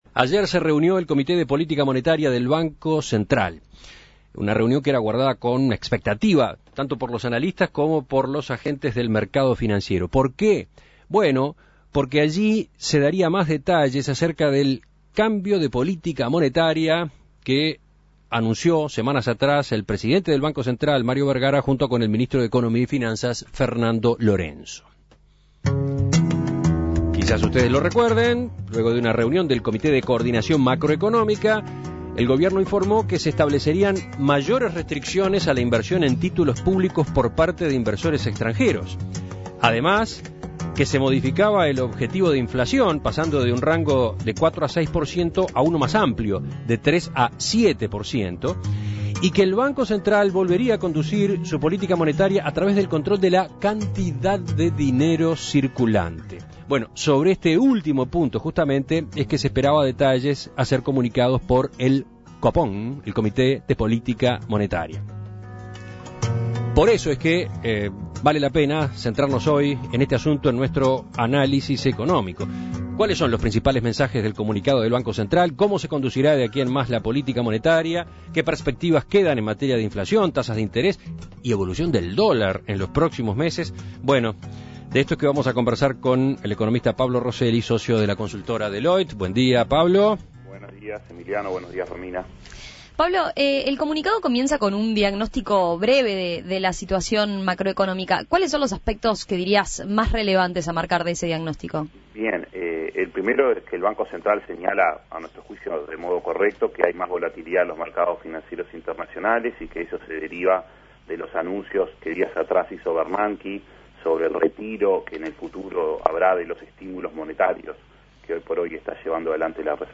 Análisis Económico ¿Qué implica el comunicado que emitió ayer el Comité de Política Monetaria del Banco Central?